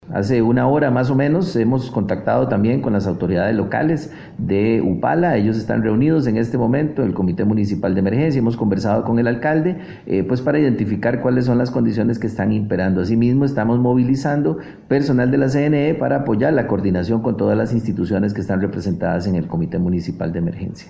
La alerta amarilla es para el Pacífico Norte, específicamente en La Cruz; así lo informó el presidente de la CNE, Alexander Solís durante la conferencia de prensa en el Consejo de Gobierno.